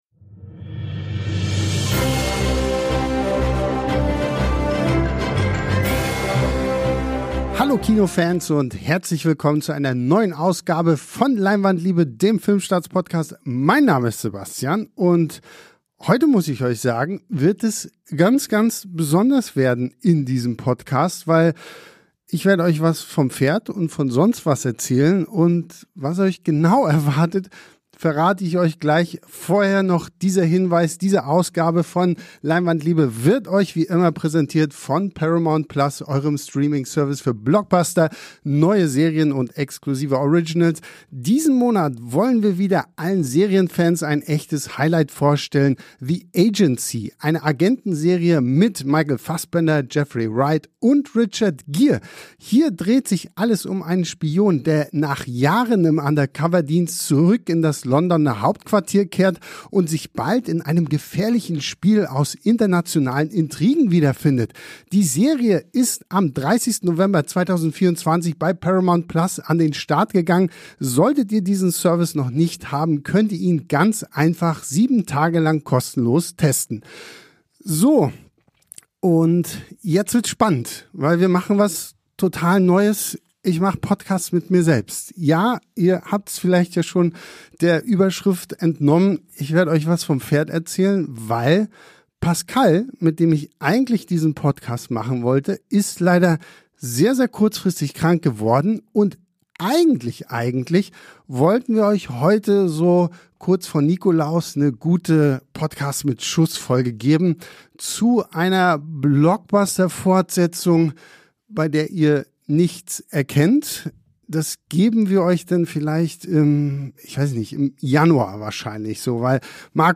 Ohne Skript, aber mit Liebe und Fachwissen.